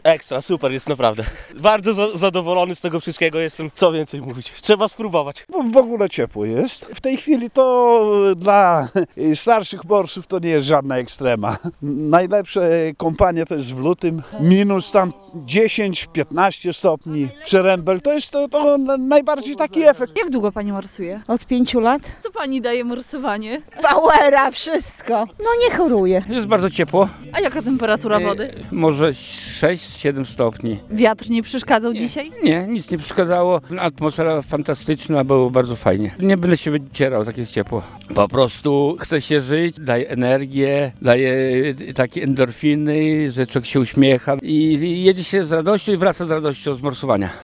– Zimno i szaruga jesienna nie są nam straszne, bo mamy gorące serca- mówią ełckie morsy.